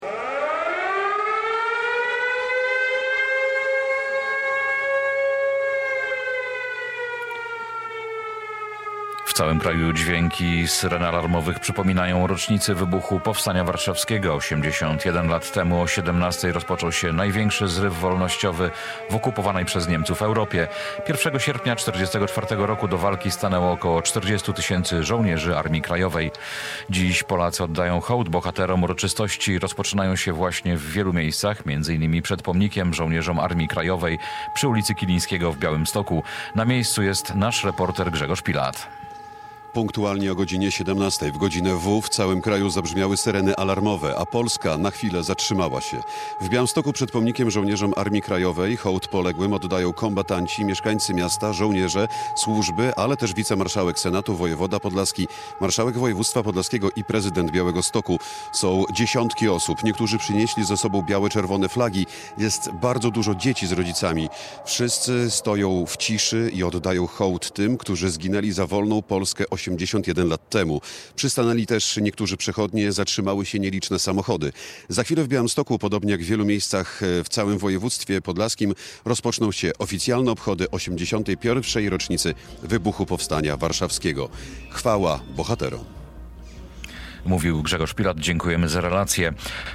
Hołd dla Powstańców Warszawskich w Białymstoku - relacja live
O 17:00 w całym mieście rozległ się dźwięk syren alarmowych, a pamięć o powstańcach została uczczona minutą ciszy.